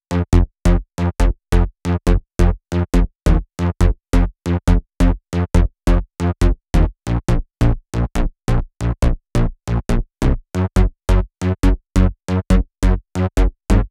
VTS1 Another Day Kit Bassline